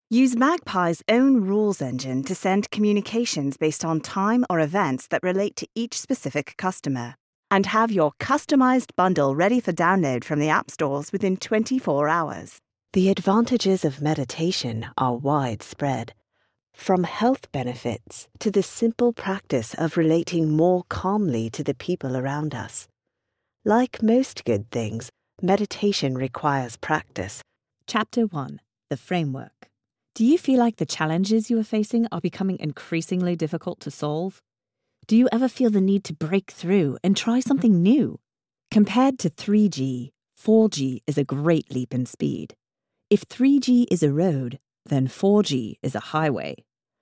Demo
Mature Adult, Adult, Young Adult
Has Own Studio
british english